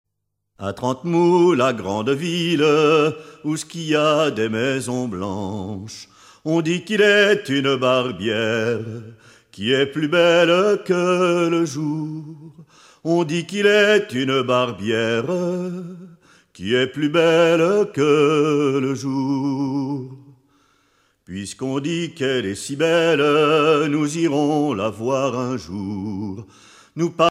circonstance : maritimes
Genre strophique
Pièce musicale éditée